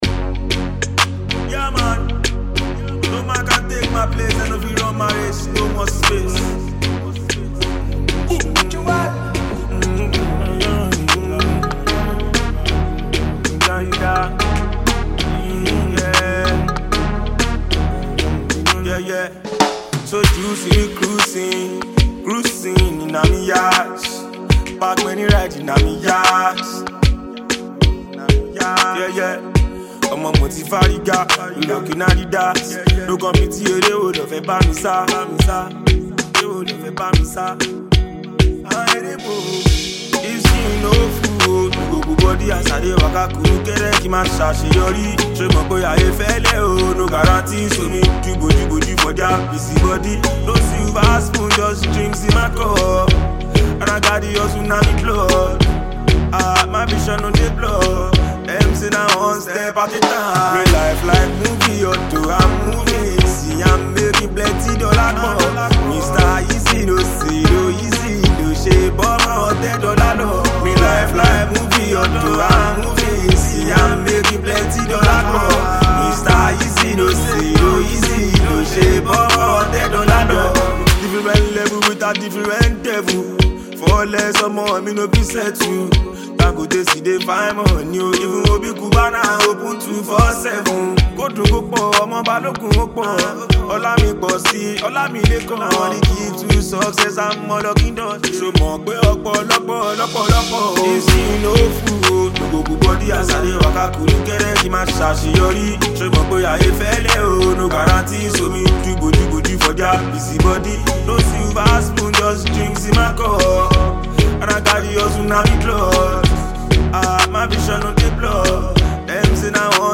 Afro-fusion